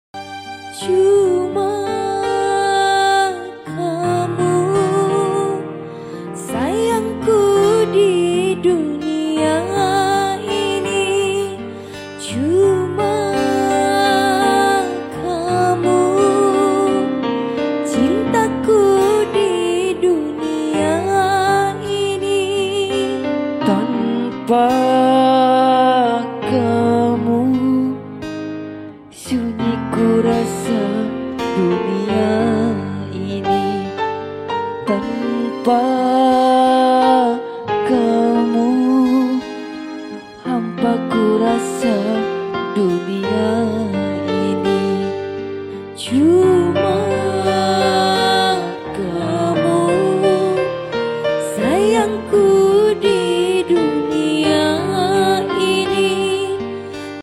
LIVE ACOUSTIC COVER
Gitar
Gitar Bass
Keyboard